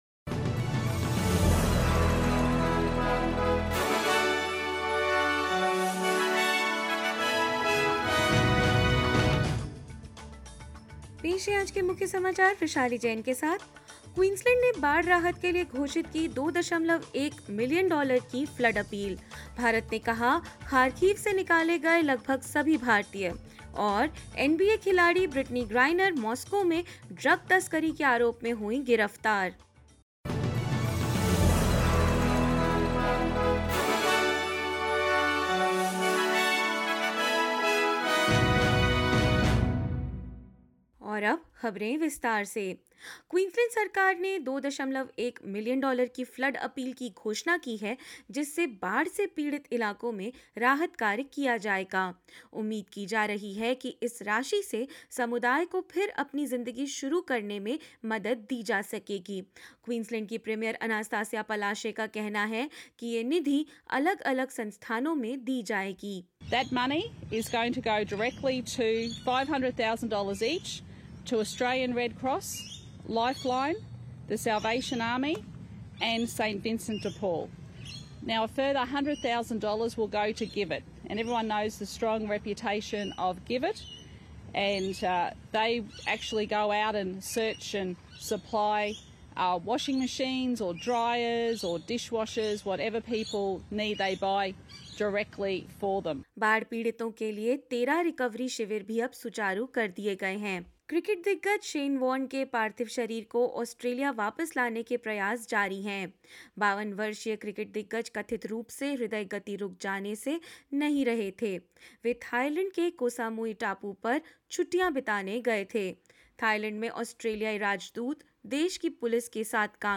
In this latest SBS Hindi bulletin: Queensland announces $2.1 million flood appeal as more wet weather is expected on the East Coast; Russia accused of breaking ceasefire, as the Ukrainian President urges citizens to fight back; NBA player Brittany Griner arrested in Moscow on drug smuggling charges and more news.
news_hindi_0603.mp3